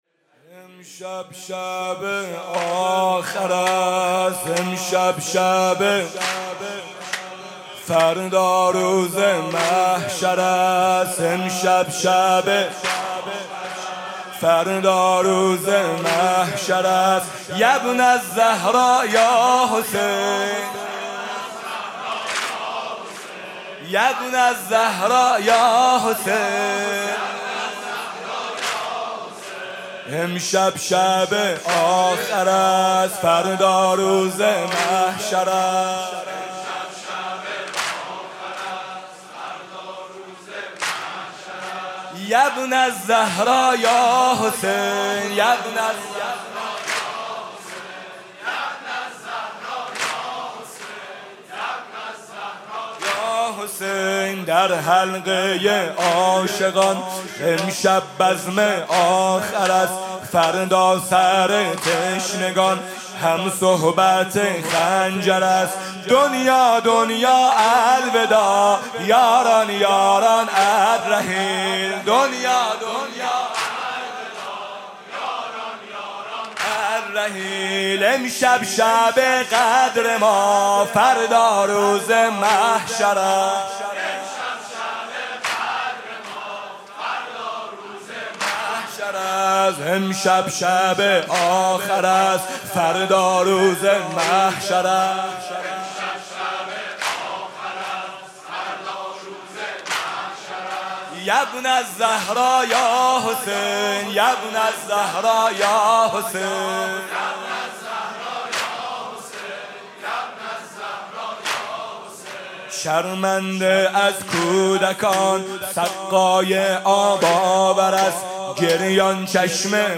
شب دهم محرم ۱۴۰۳
music-icon واحد: امشب شب آخر است فردا روز محشر است